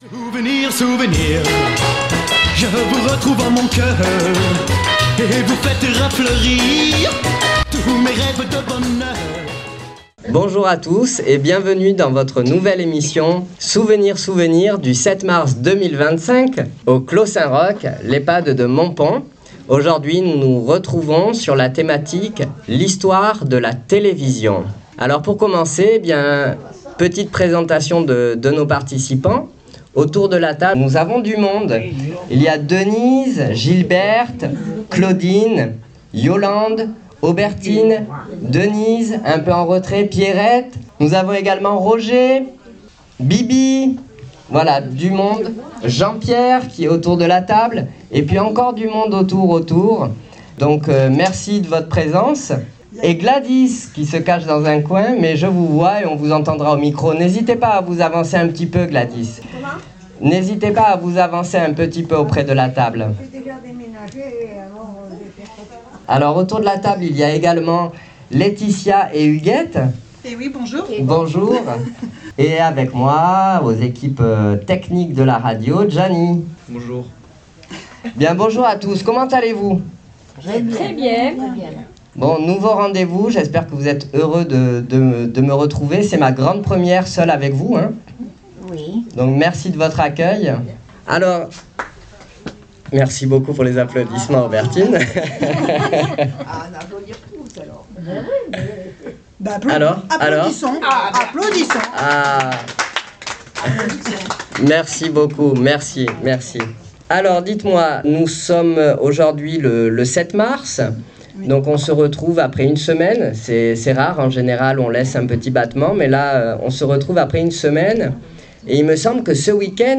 Souvenirs Souvenirs 06.03.26 à l'Ehpad de Montpon " L'histoire de la télé "